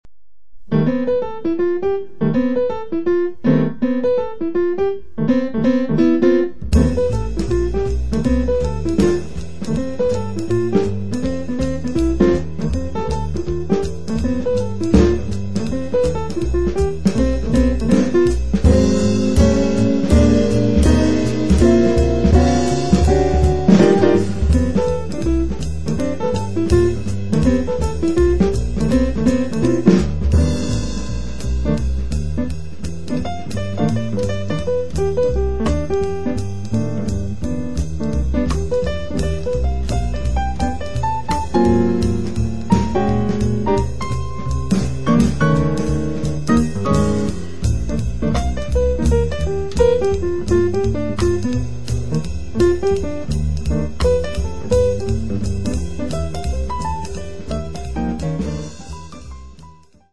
Chitarra
Hammond B3
Pianoforte
Contrabbasso
Batteria